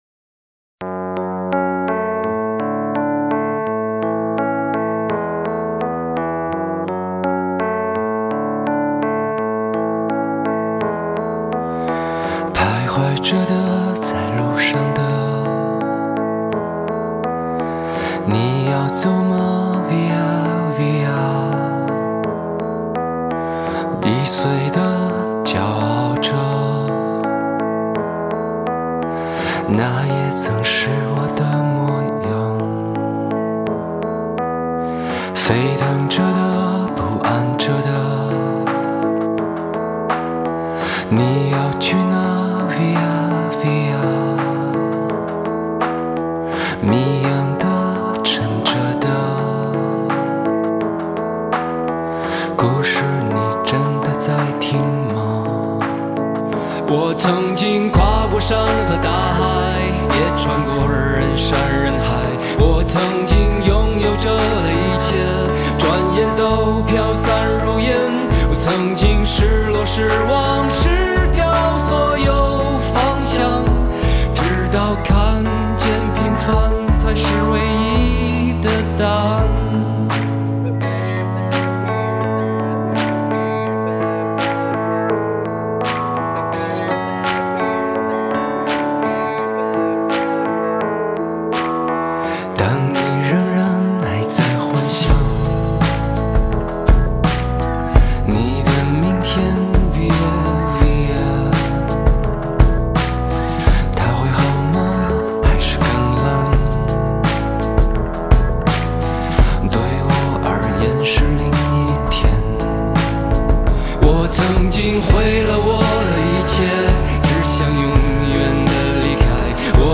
“新民谣”式风格，缓慢抒情的节奏融入其清澈的嗓音及低声的吟唱，令歌曲充满淡淡的温暖及忧伤。